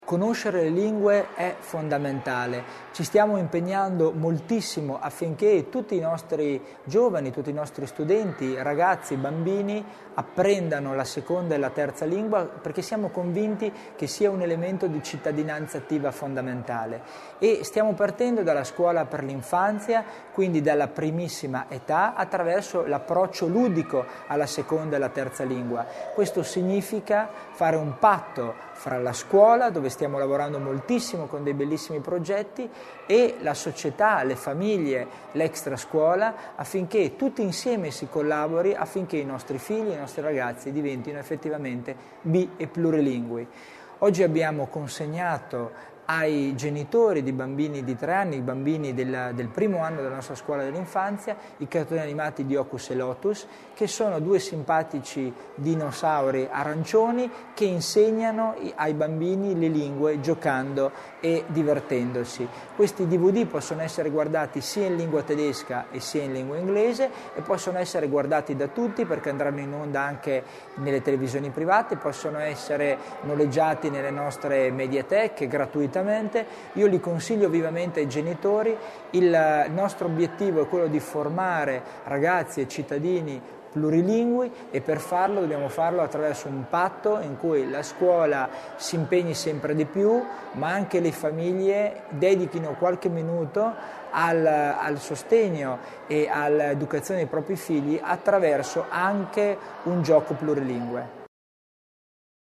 Il Vicepresidente Tommasini sul valore del progetto